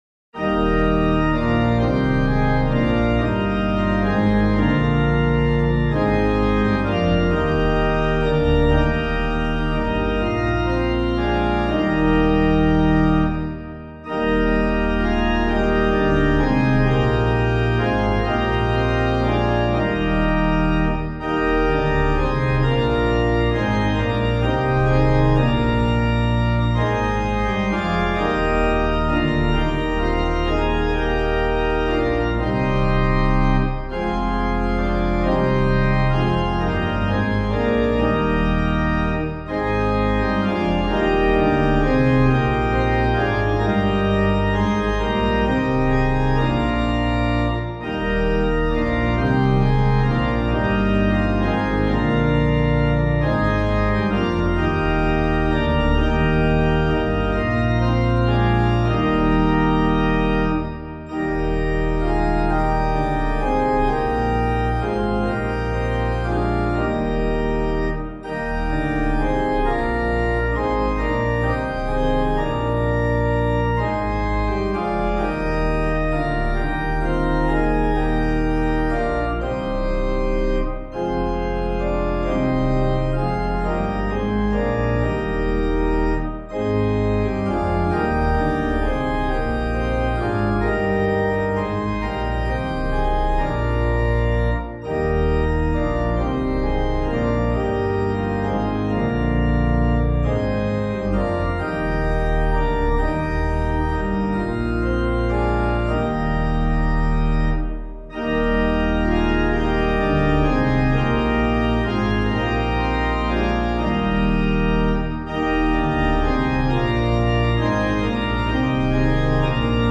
chant, Mode V
organpiano